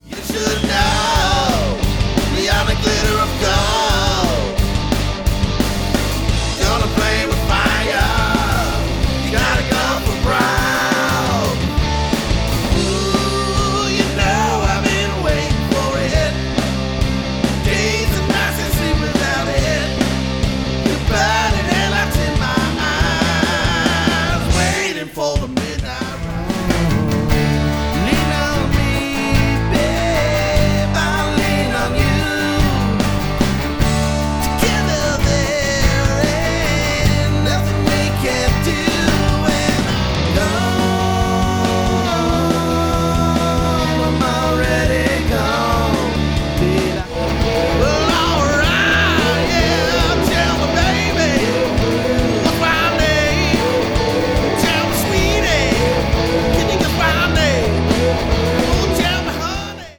Guitars
Vocals, Bass, Drum programming